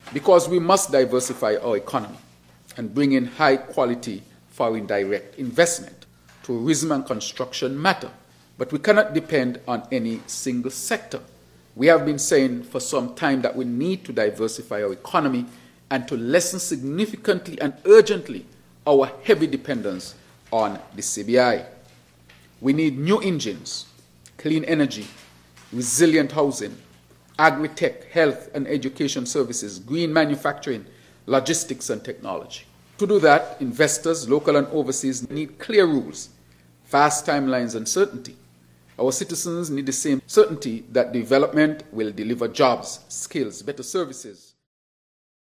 Prime Minister of Saint Kitts and Nevis, the Hon. Dr. Terrance Drew answered the question: “Why do we need Special Sustainability Zones?”